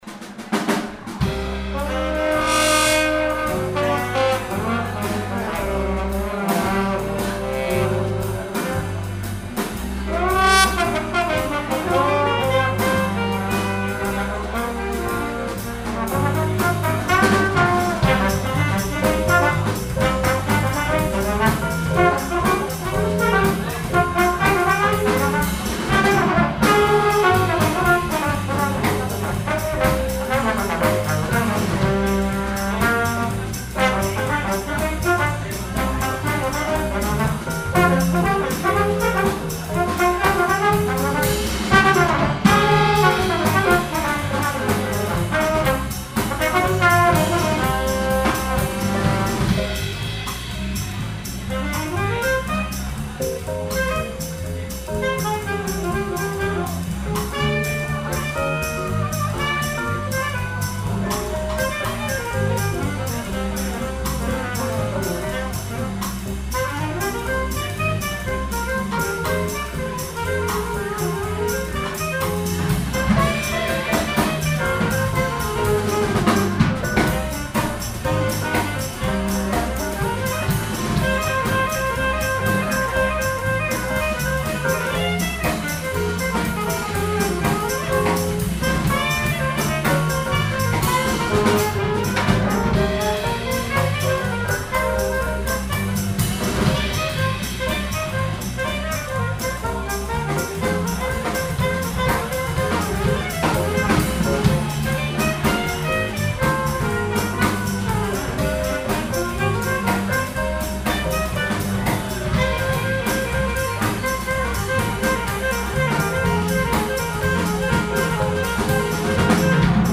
Stilrichtungen: Blues, Bossa, Bebop, Balladen ...wie